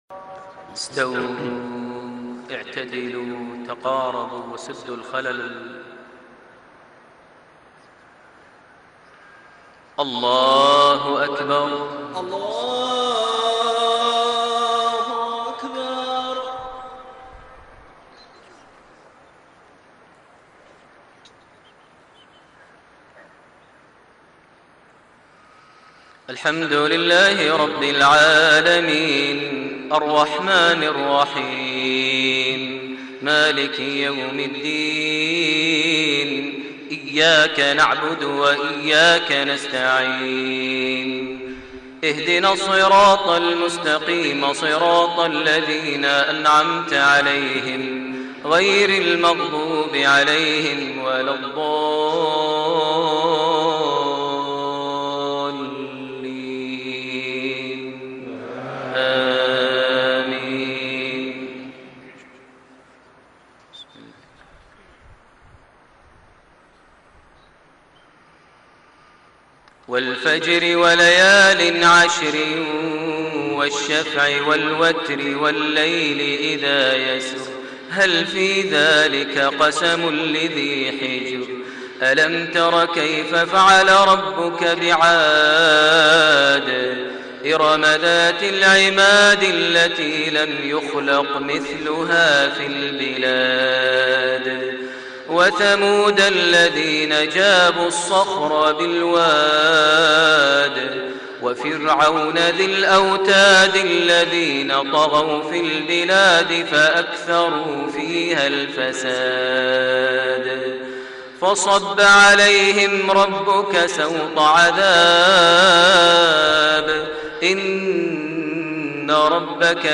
صلاة الفجر 28 رجب 1432هـ | سورتي الفجر و البلد > 1432 هـ > الفروض - تلاوات ماهر المعيقلي